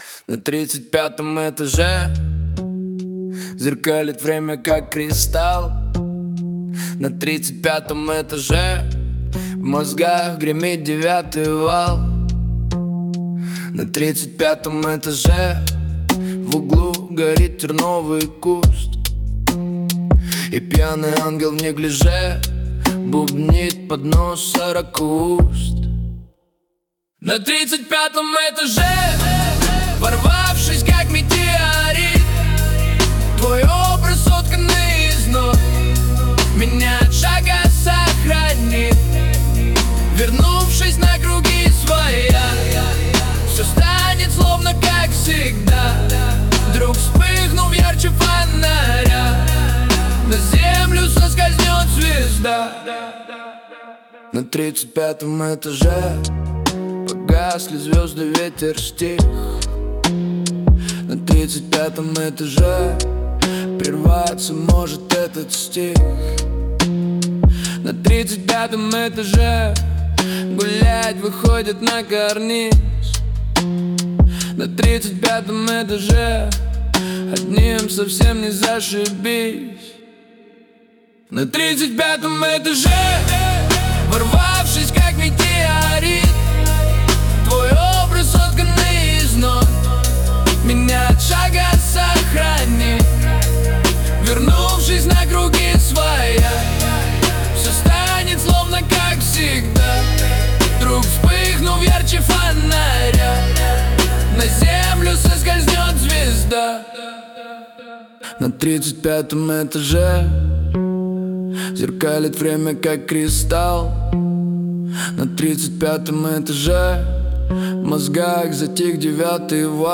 2) Ганста-рок
ганста-рэп с рок-аранжировкой, средний темп, плотный бит и бас, грязные синтезаторные аккорды, агрессивная подача, припев с подъёмом, гитарные вставки